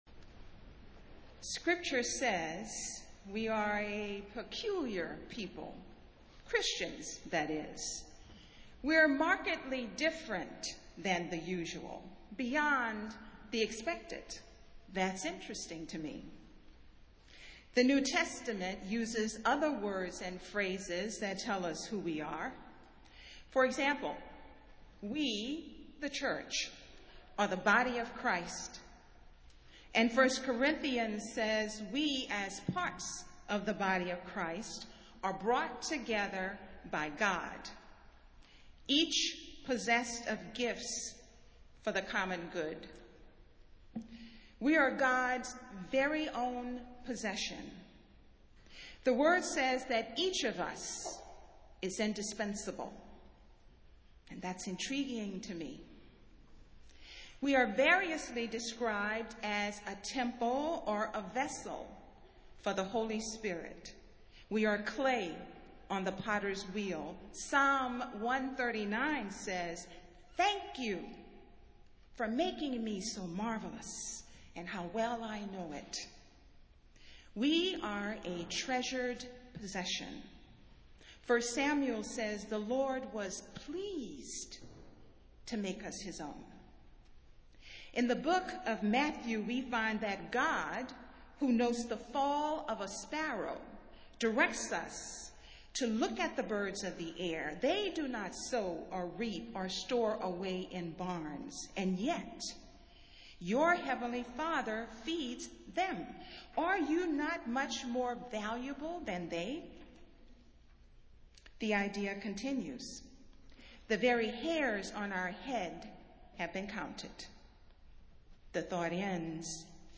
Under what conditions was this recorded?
Festival Worship - Consecration Sunday